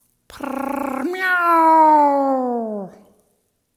meows-5.mp3